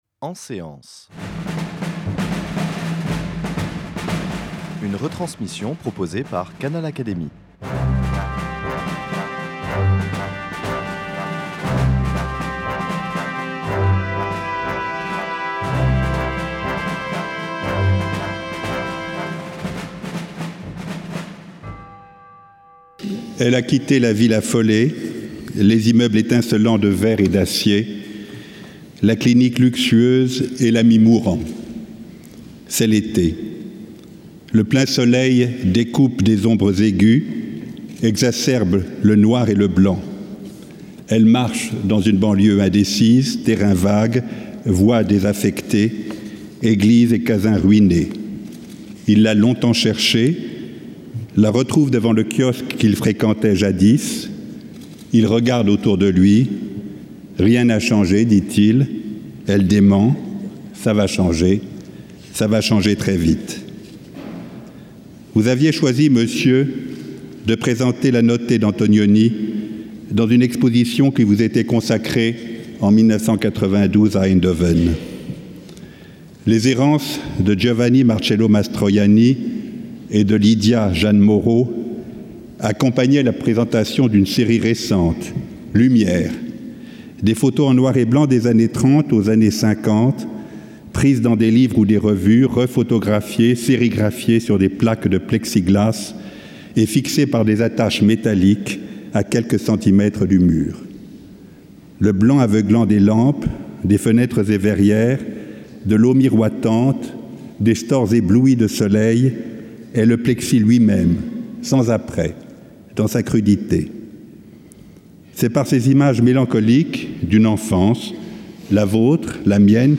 Cérémonie d’installation de Jean-Marc Bustamante à l’Académie des beaux-arts.
Au cours de cette cérémonie sous la Coupole de l’Institut de France, Henri Loyrette a prononcé le discours d’installation de Jean-Marc Bustamante avant d’inviter ce dernier à faire, selon l’usage, l’éloge de son prédécesseur, le peintre Zao Wou-Ki décédé le 9 avril 2013.